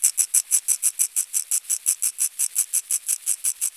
• maracas studio shaker loop.wav
Recorded in a professional studio with a Tascam DR 40 linear PCM recorder.
maracas_studio_shaker_loop_Wu7.wav